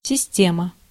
Ääntäminen
Synonyymit infrastructure machine période Ääntäminen France Tuntematon aksentti: IPA: /sis.tɛm/ Haettu sana löytyi näillä lähdekielillä: ranska Käännös Ääninäyte 1. система {f} (sistema) Suku: m .